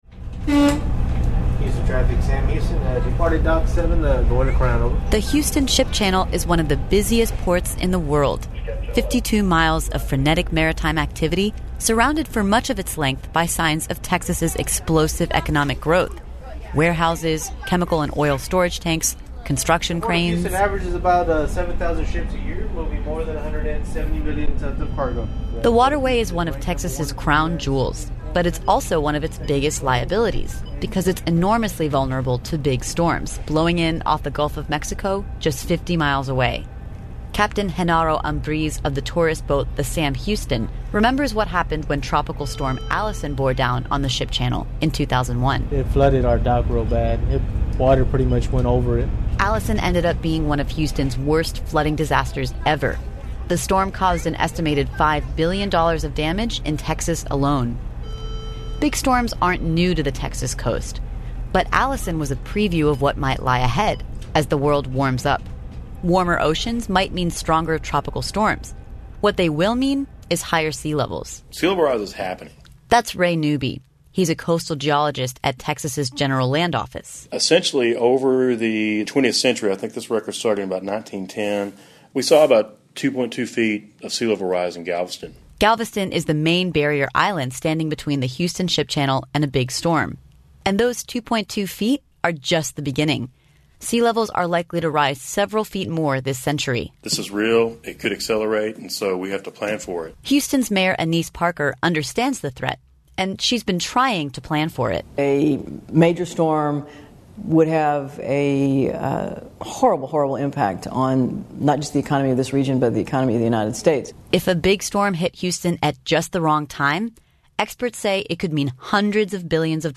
Radio
story from The Word, produced by Pubic Radio.